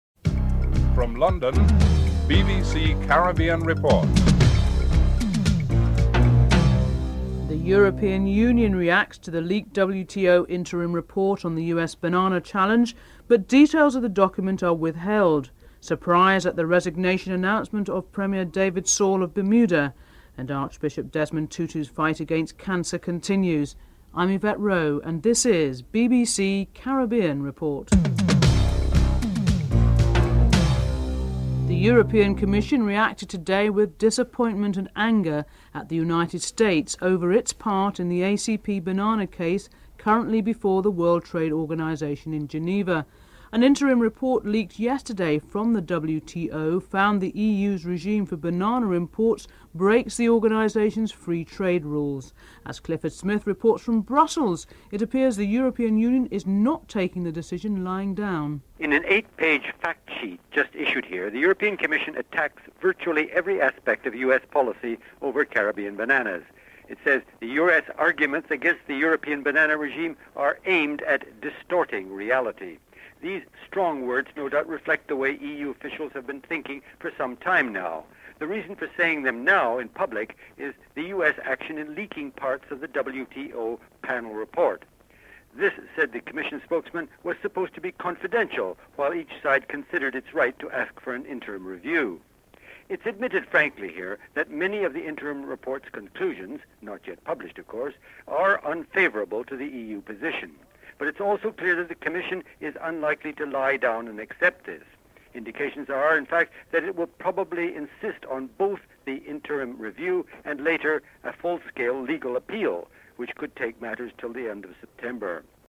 3. The banana industry in the Caribbean region is concerned about the effects of a final World Trade Organisation ruling on the banana regime. OECS Ambassador, Edwin Lura is interviewed (03:16-05:35)